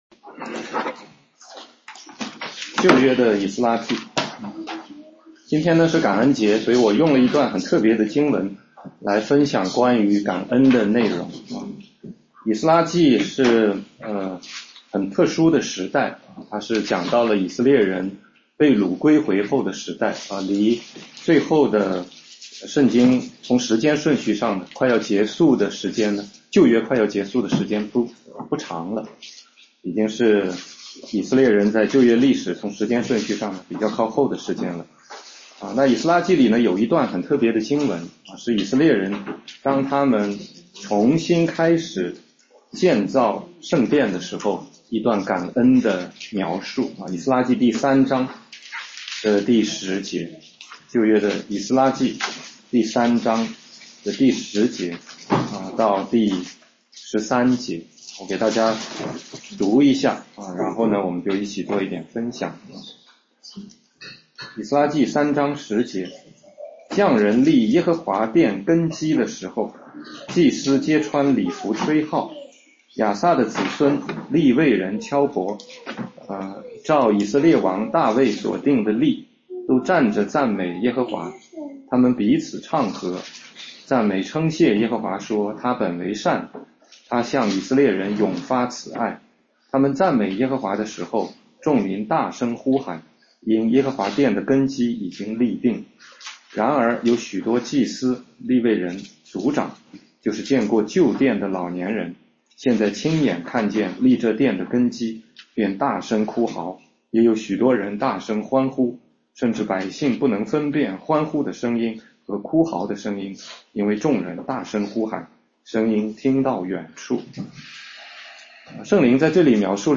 16街讲道录音 - 感恩的赞美